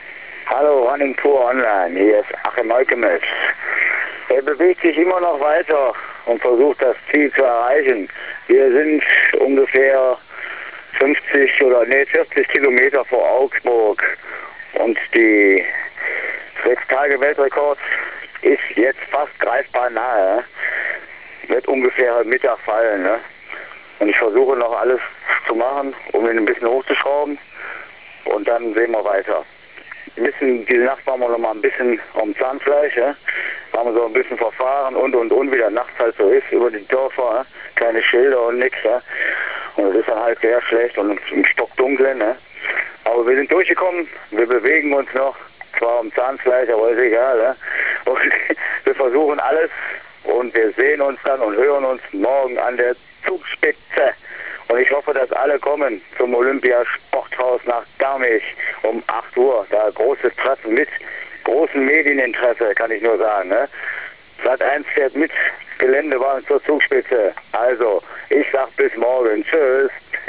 running-pur ONLINE war mit einem Kamerateam live dabei.